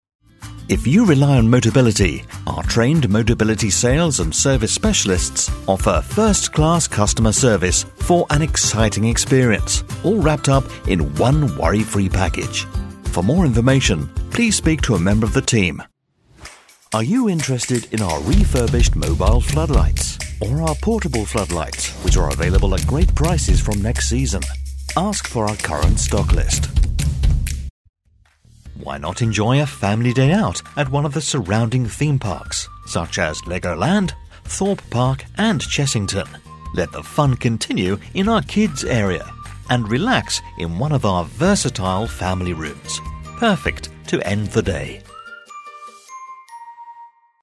Professional On Hold Phone Message | Business Phone Greetings & Recordings
Warm, smooth, deep, clear, distinctive (not stuffy!) British narrator.